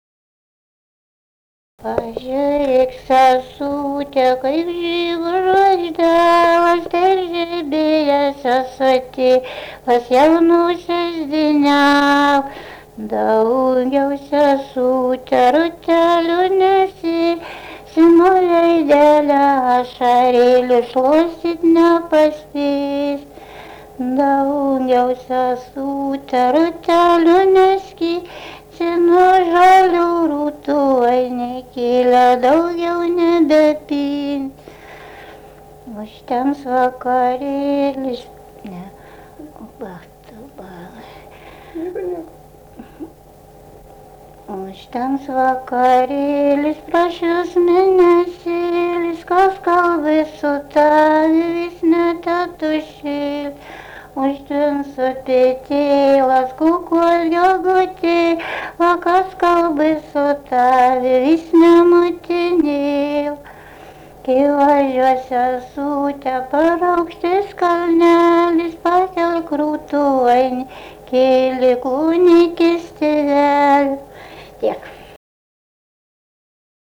daina, vestuvių
Sereikoniai
vokalinis